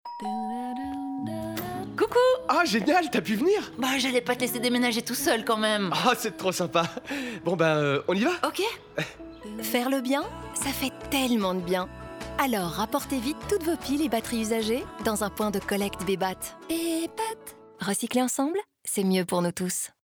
Sound Production & Sound Design: La Vita Studios
250324-Bebat-radio-mix-OLA--23LUFS-demenagement-FR-20.mp3